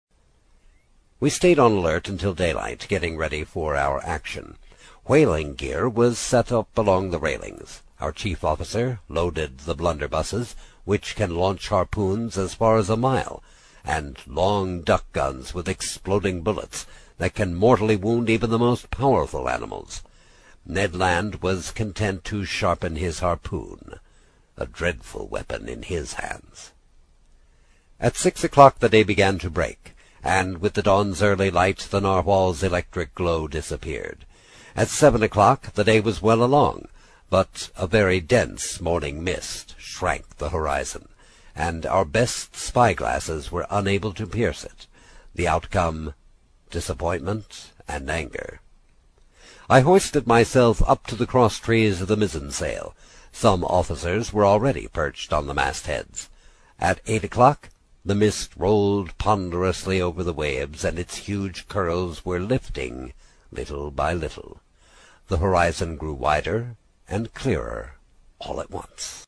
在线英语听力室英语听书《海底两万里》第66期 第6章 开足马力(8)的听力文件下载,《海底两万里》中英双语有声读物附MP3下载